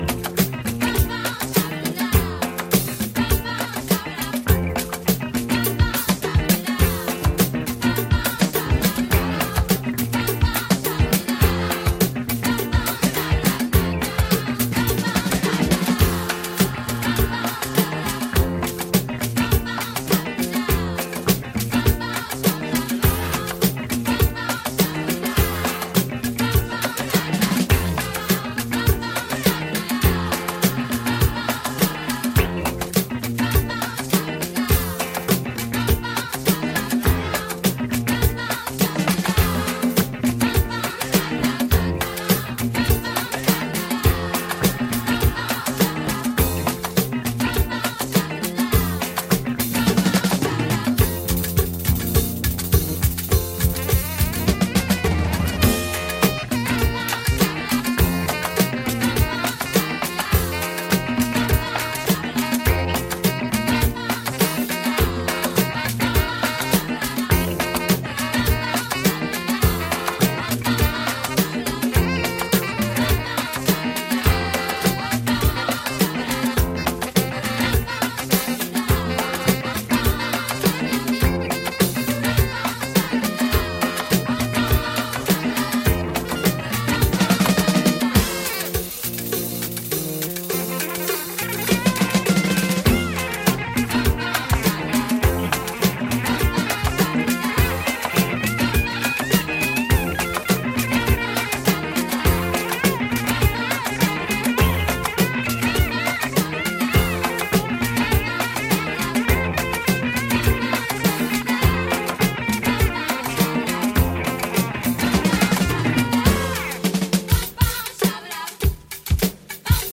女性ヴォーカルをフィーチャーしたDJ的にもプレイしやすいグルーヴィーなアフロ・ディスコ〜ファンクを展開！